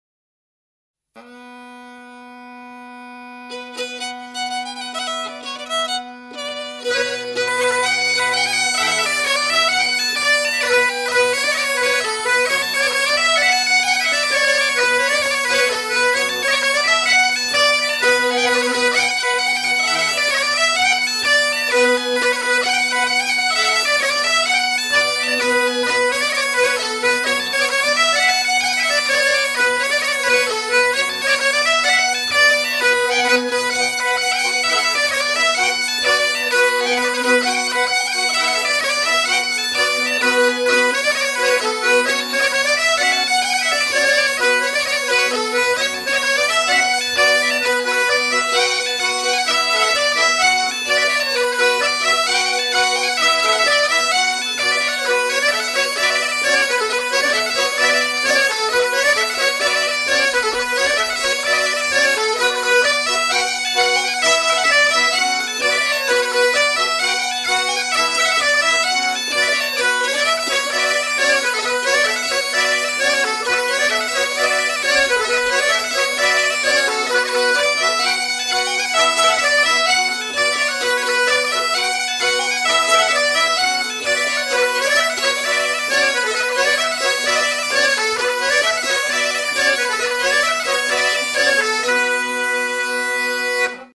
Deux airs à danser le branle, courante ou Maraîchine
danse : branle : courante, maraîchine